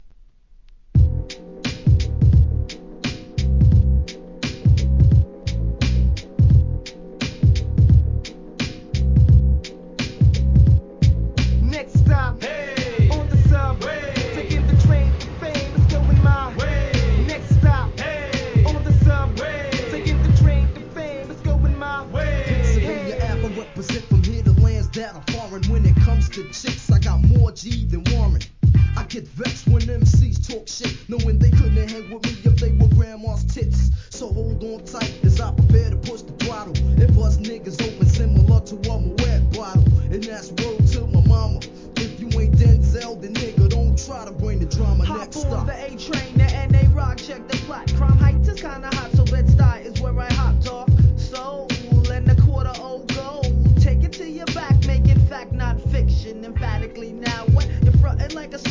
HIP HOP/R&B
浮遊感漂う'90s良質アングラ!!!